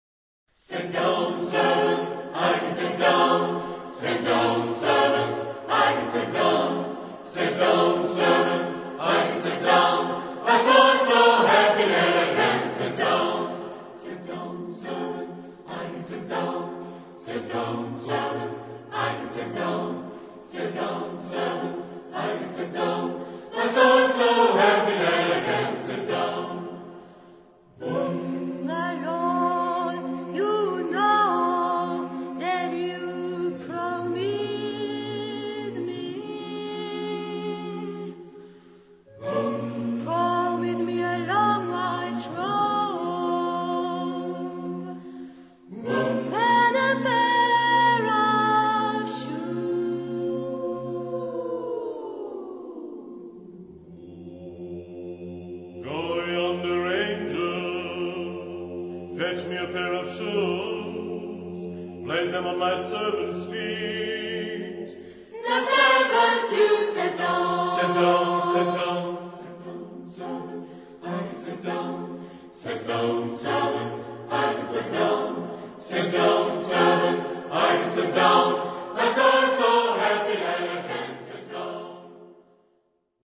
Caractère de la pièce : rythmé ; vivant
Type de choeur : SSAATTBB  (4 voix mixtes )
Solistes : Alto (1)/Basse (1)  (2 soliste(s))
Instruments : Piano (1)
Tonalité : fa mineur